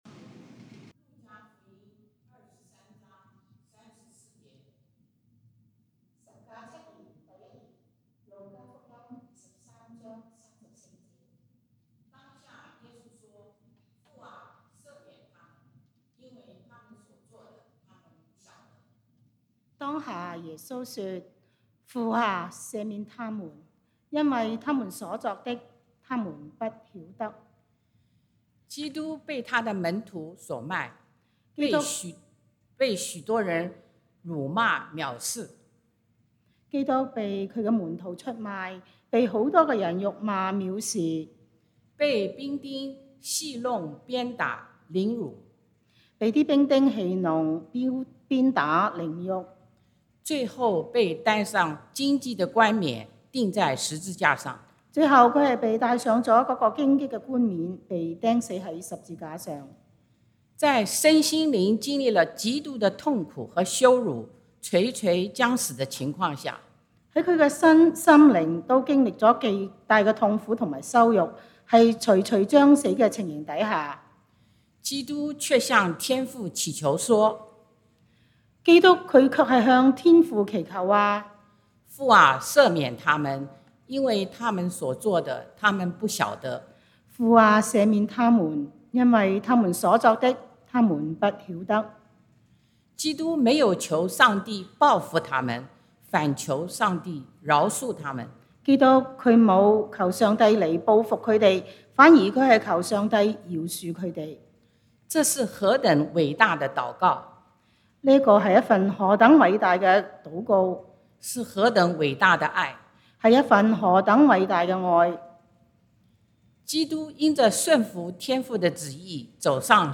受苦節崇拜 十架七言 第一言： 當下耶穌說：「父啊，赦免他們，因為他們所作的，他們不曉得。」